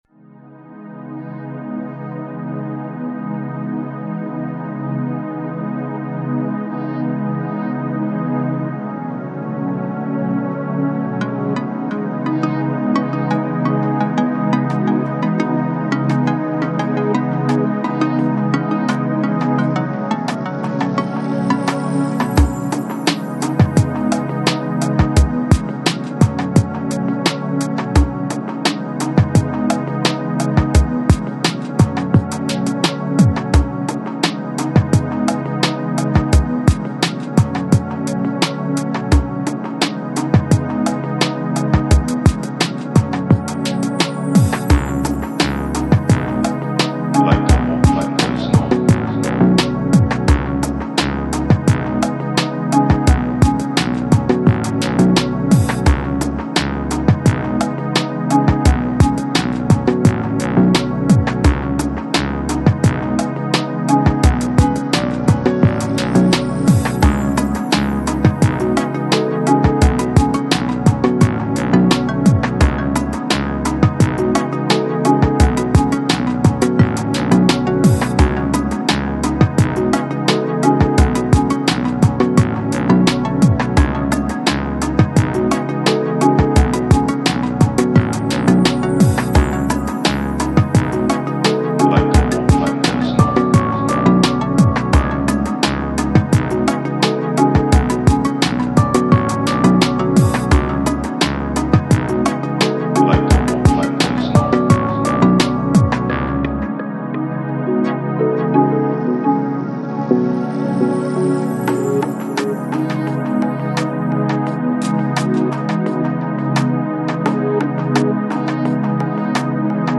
Electronic, Chill Out, Lounge, Downtempo Год издания